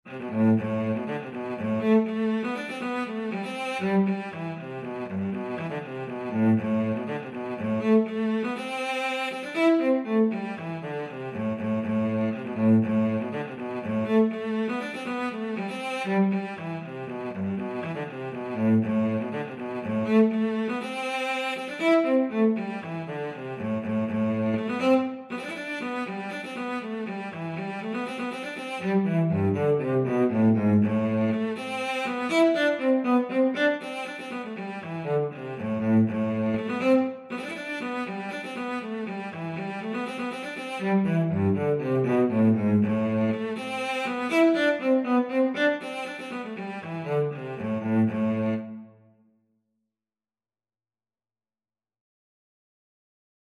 6/8 (View more 6/8 Music)
Cello  (View more Easy Cello Music)
Traditional (View more Traditional Cello Music)